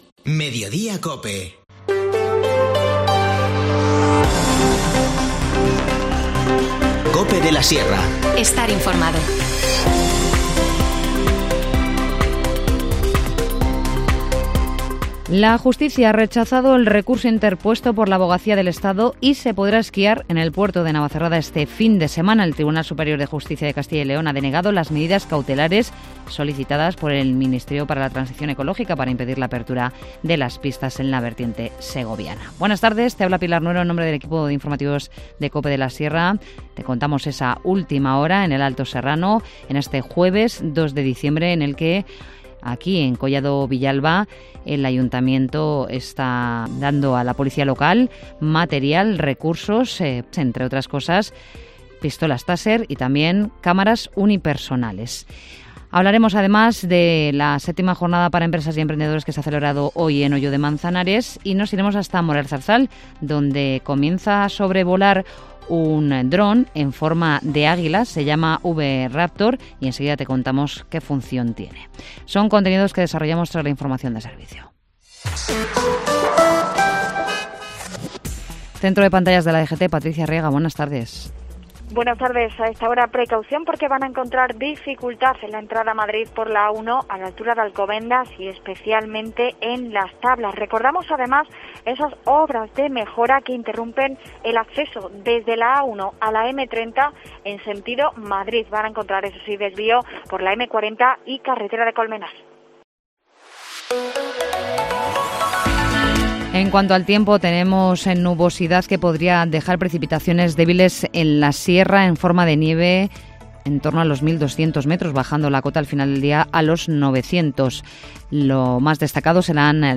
Informativo Mediodía 2 diciembre